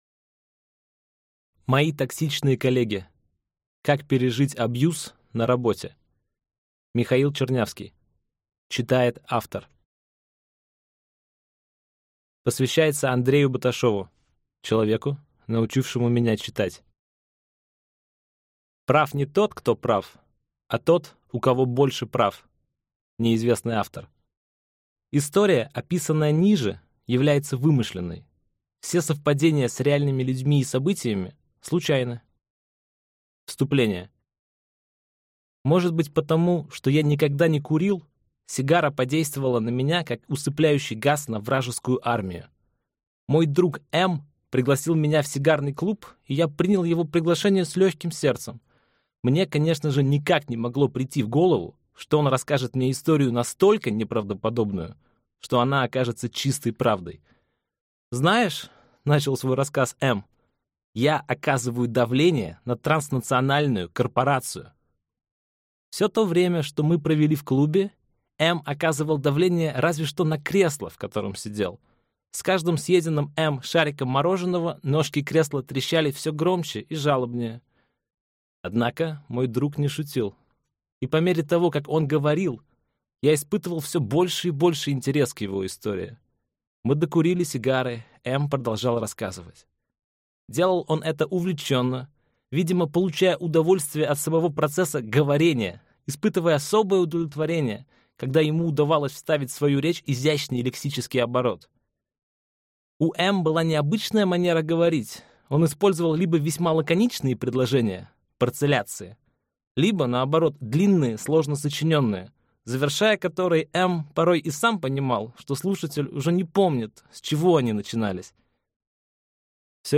Аудиокнига Мои токсичные коллеги. Как пережить abuse на работе?